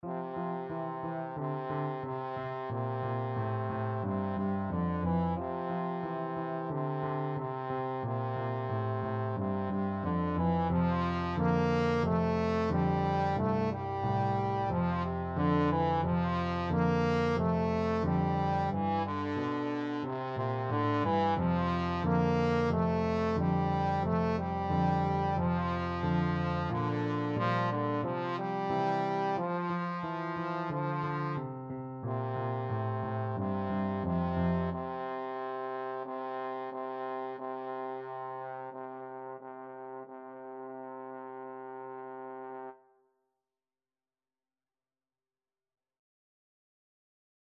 A beginners piece with a rock-like descending bass line.
4/4 (View more 4/4 Music)
March-like = 90
Pop (View more Pop Trombone Music)